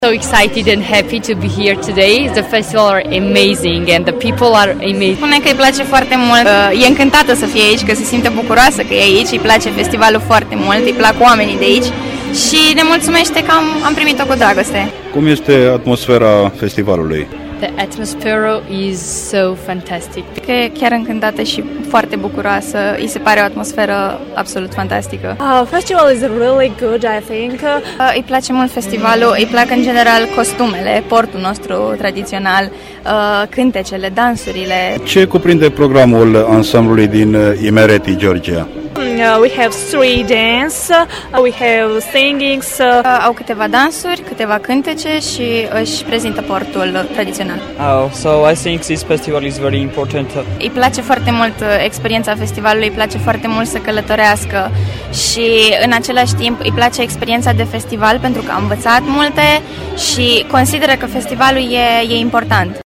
Aflați pentru prima dată în țara noastră și la Herculane, tinerii georgieni s-au arătat încântați de această experiență a lor :
tinere-georgiene.mp3